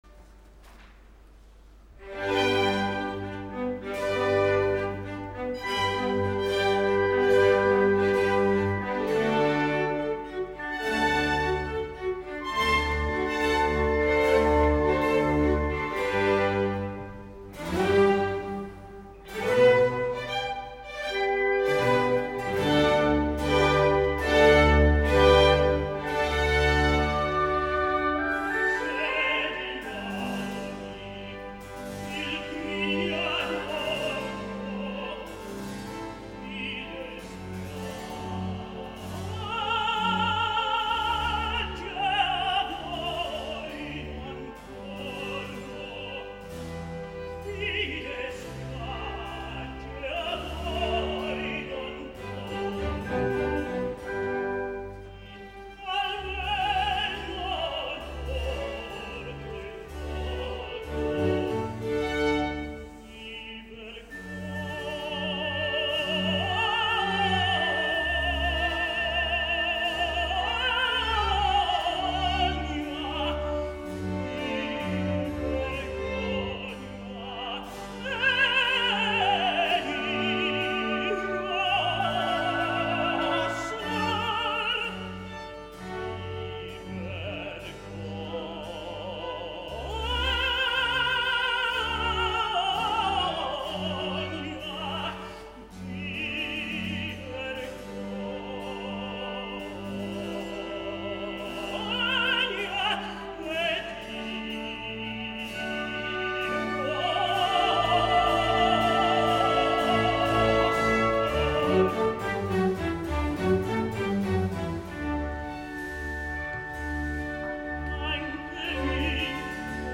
Mozart demana clarament un baritenor capaç de fer totes les notes escrites, totes les escales i els salts, amb ímpetu i orgull, amb la serenitat que el personatge poderós, encara que ferit en l’orgull, ha de demostrar en la seva entrada en escena i sobretot amb el virtuosisme extrem que el compositor exigeix al tenor protagonista.
En aquest cas, Barry Banks emmirallant-se amb el grandiós Rockwell Blake (i qui no?), ens ofereix una notable versió,  cuidada, serena i molt introspectiva, convertint aquesta cavata en quasi un lament i potser allunyant-se de les magnífiques versions que trobareu al final d’aquest petit apunt.
Certament Banks no és un baritenor i pateix una mica en la zona greu, on la veu perd projecció, com podreu apreciar, però tan aviat es situa en la zona central i aguda, la brillantor de l’emissió és molt notable.
La versió que escoltareu va tenir lloc abans d’ahir (21 de juliol) al Prinzregententheate de Munic, i l’acompanya la Bayerisches Staatsorchester dirigida per Ivor Bolton.